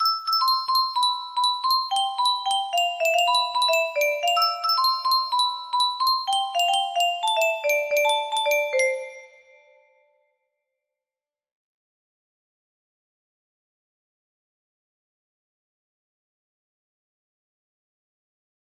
Honeypot music box melody